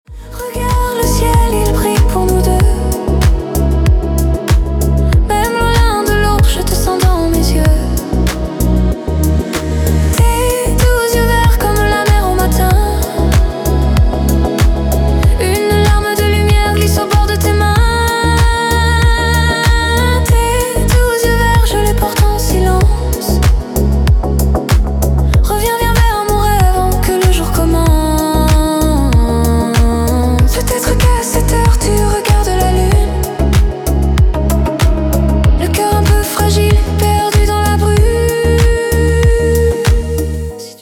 Зарубежные рингтоны